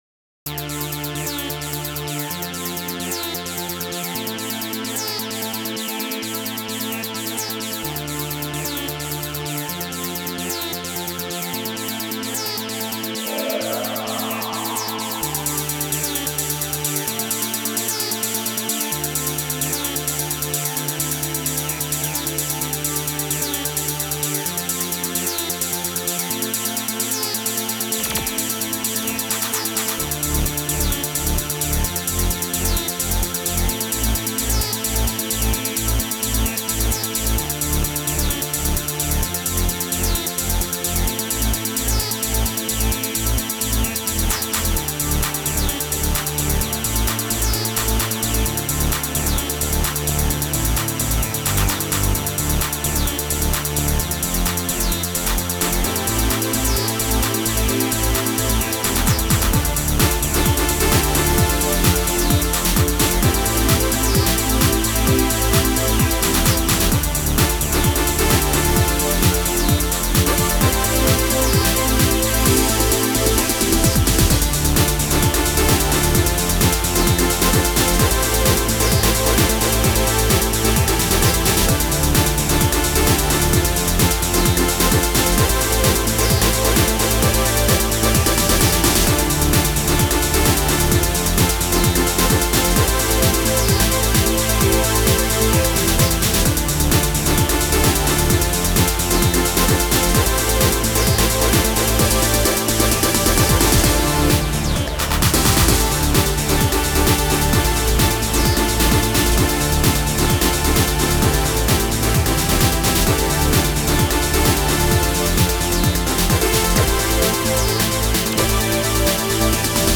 Style: Synthpop